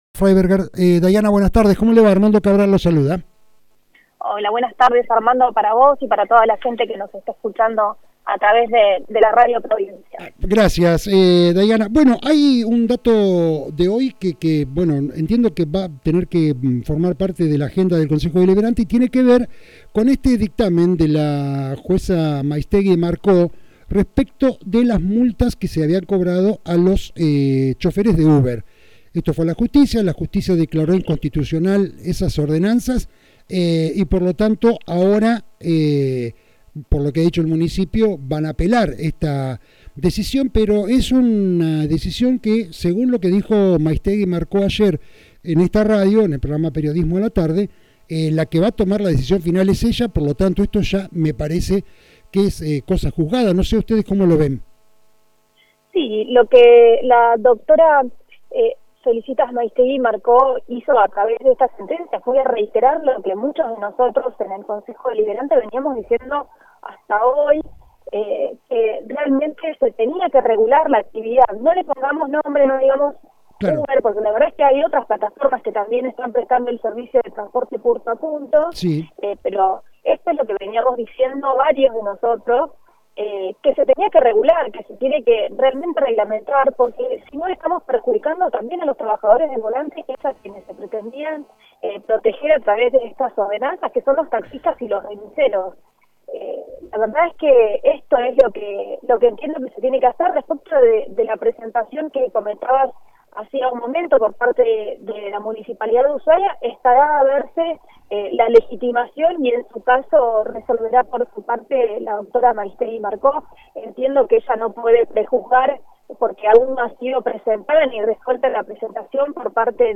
Rio Grande 19/08/2024.- En diálogo con Resumen Económico, por Radio Provincia, la edil de Ushuaia, e integrante del Partido Solidario, se refirio a la resolucion de la justicia, respecto de la plataforma UBER, informando a la Municipalidad capitalina, que las ordenanzas prohibiendo su funcionamiento quedaron sin efecto y a lo que se municipio, respondió que apelaran la medida.